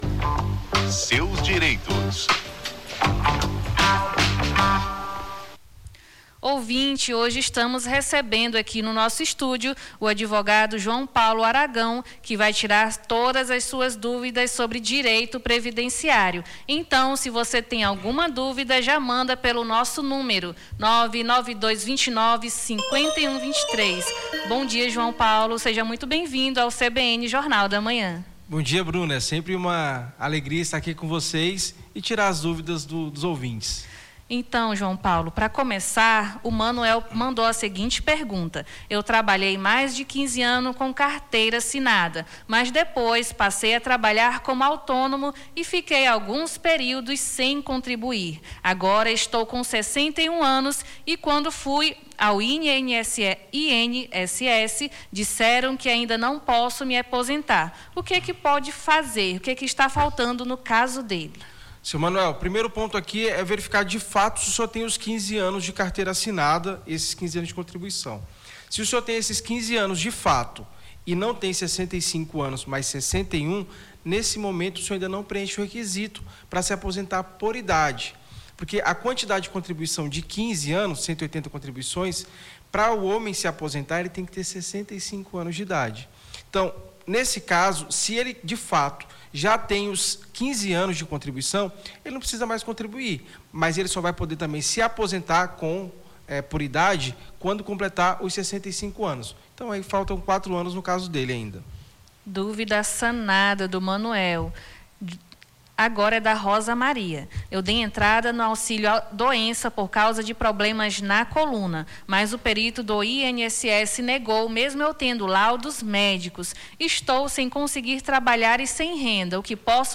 Seus Direitos: advogado esclarece dúvidas sobre direito previdenciário